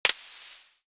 Sizzle Click 3.wav